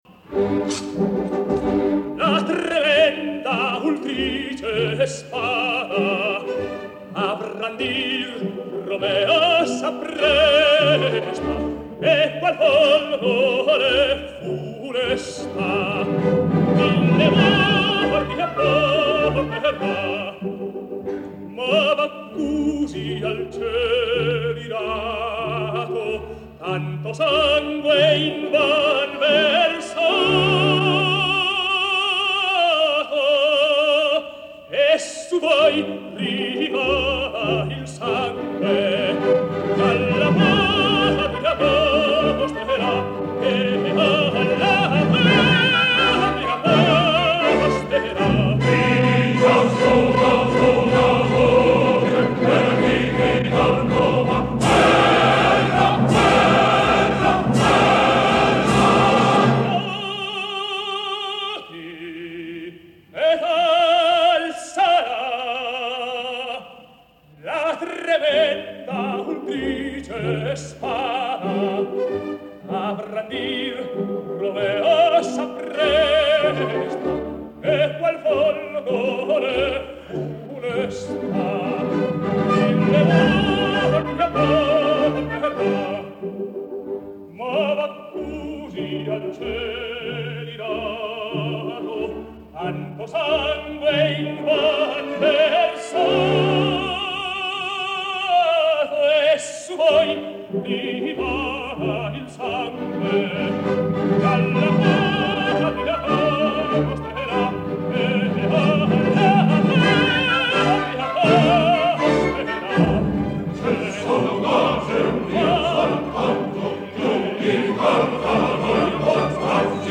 Обычно партию Ромео поют меццо-сопрано, но здесь представлено и исполнение арии Ромео тенором.